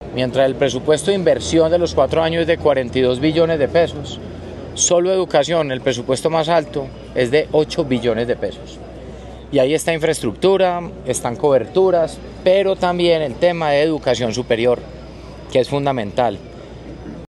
Declaraciones-del-alcalde-de-Medellin-Federico-Gutierrez-Zuluaga-sobre-Infraestructura-Educativa.mp3